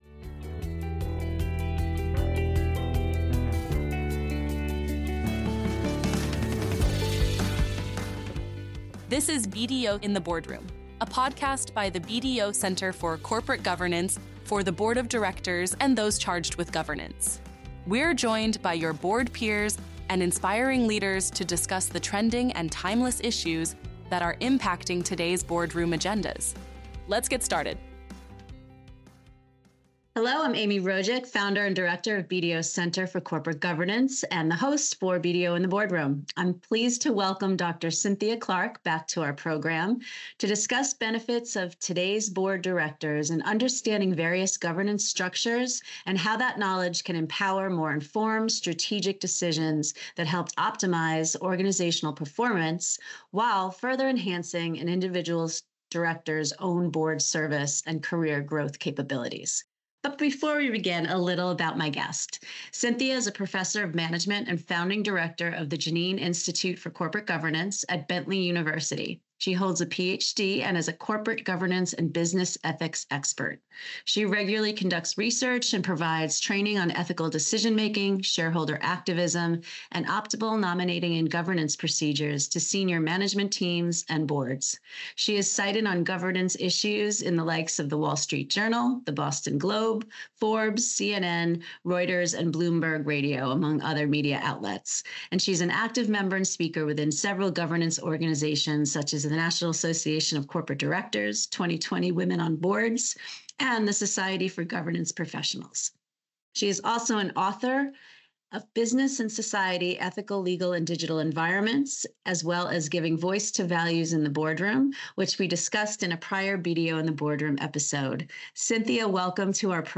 BDO in the Boardroom is a podcast series for the board of directors and those charged with governance. Each episode features a topical discussion with board peers and subject matter experts on both trending and timeless boardroom issues – mitigating risk in an increasingly digital world, navigating your board career, financial and ESG reporting, shareholder activism and more.